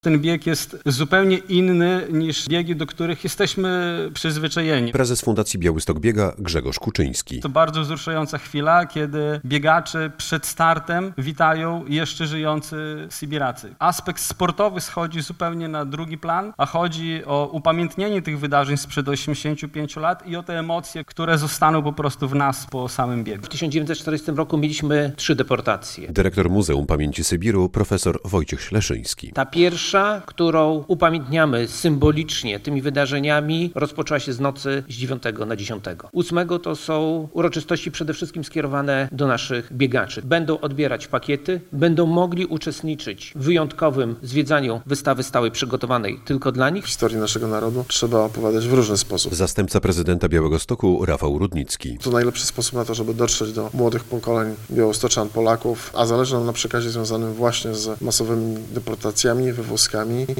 Konferencja prasowa przed Biegiem Pamięci Sybiru - relacja